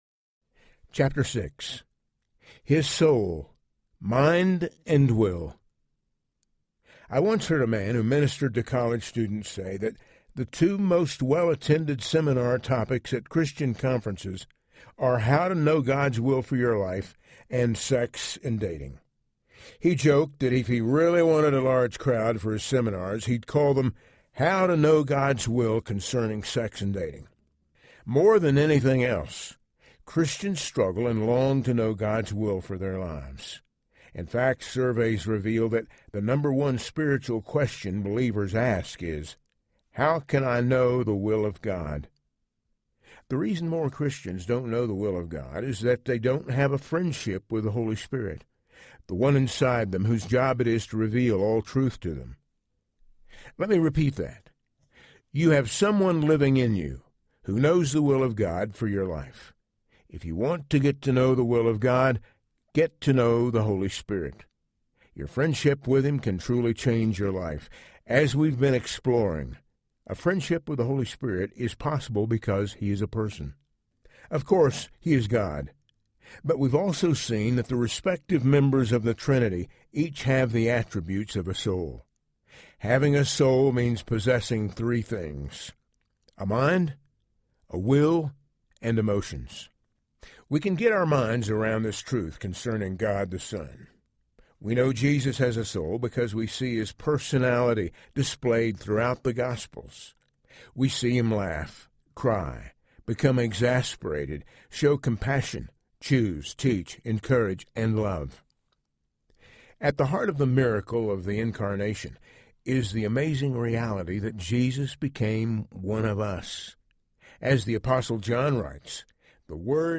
The God I Never Knew Audiobook
Narrator
5.90 Hrs. – Unabridged